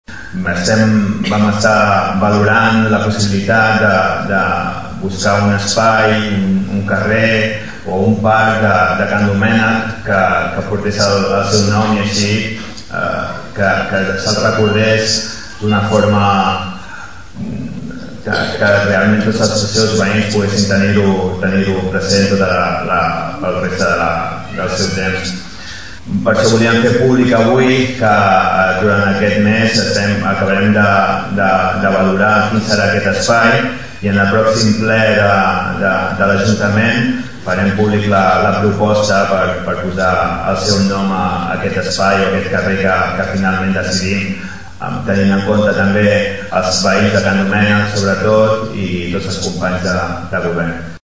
Va ser un acte senzill, però emotiu: diversos parlaments i algunes projeccions amb imatges de la seva vida política, acompanyades de música en directe, van servir per recordar-lo.